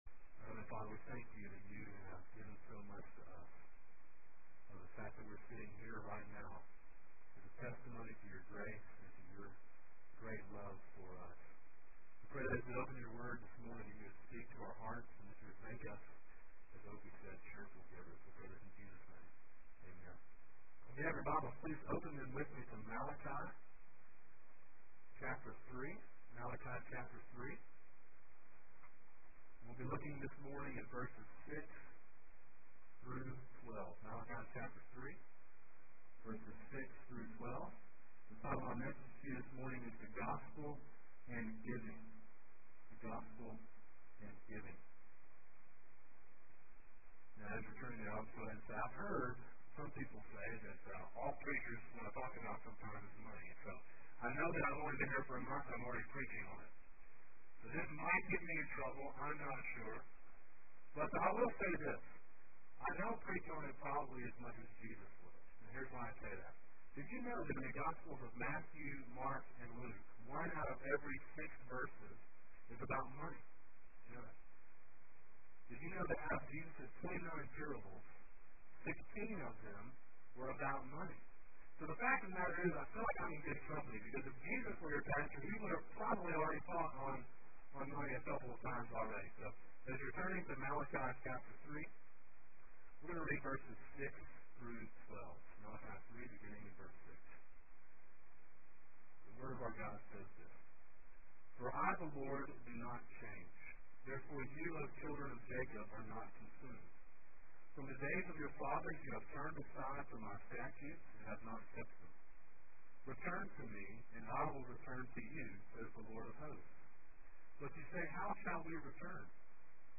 Sermon Audio: “The Gospel & Giving,” Malachi 3:6-12 – Calvary Baptist Church